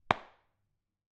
冲压 " 冲压007
描述：打孔的声音。
Tag: SFX 冲头 一巴掌